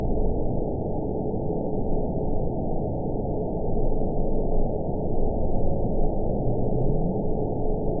event 920508 date 03/28/24 time 10:18:23 GMT (1 month ago) score 9.34 location TSS-AB07 detected by nrw target species NRW annotations +NRW Spectrogram: Frequency (kHz) vs. Time (s) audio not available .wav